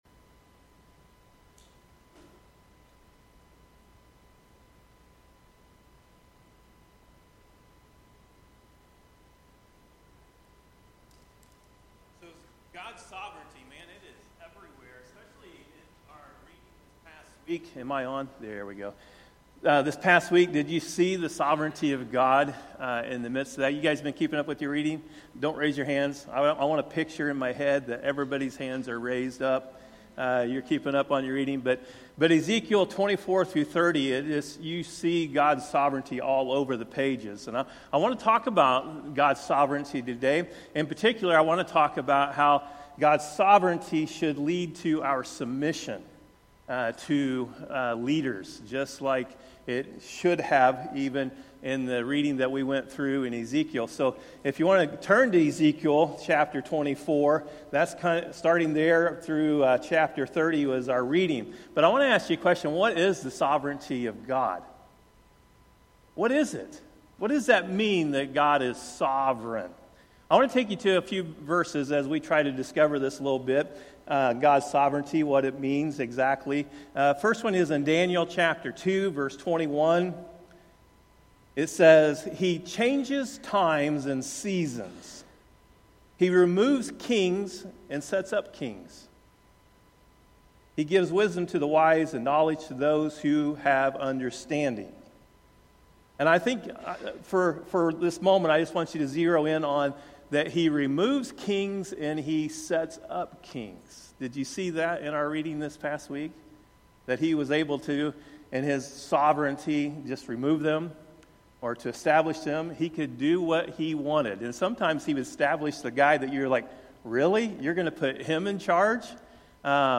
Sermons by Westside Christian Church